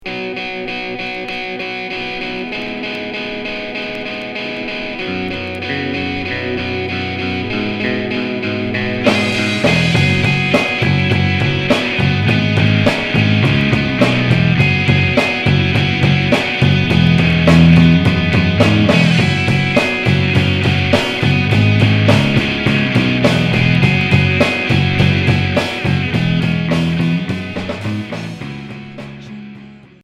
Noisy pop